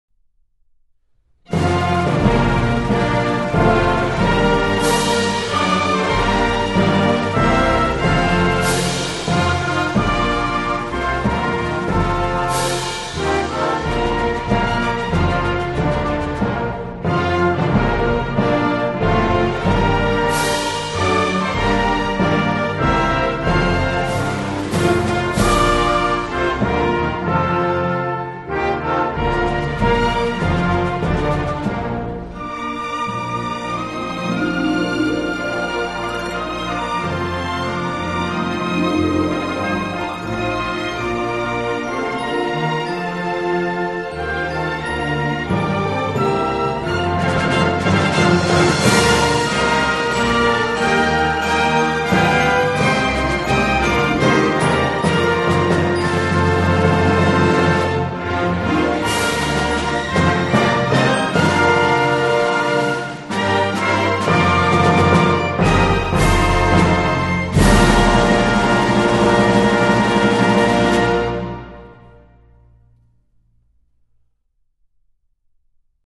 国歌 星条旗